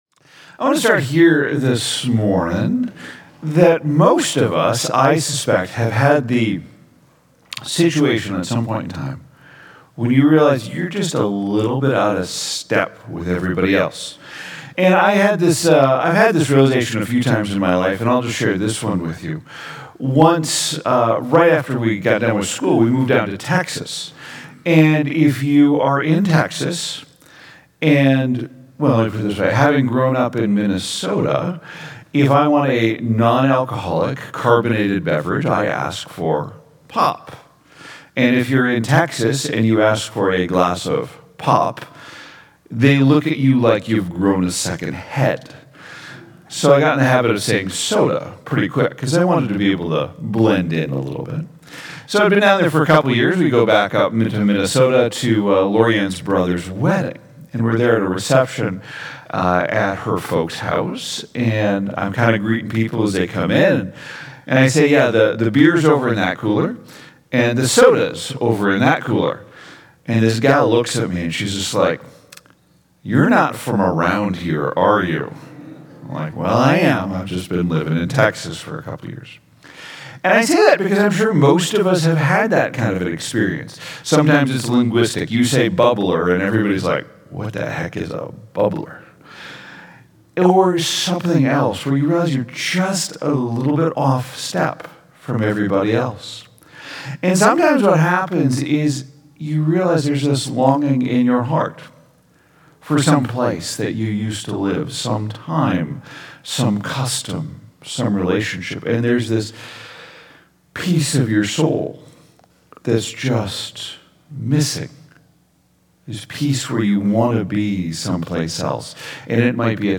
Sermon Series: Life of King David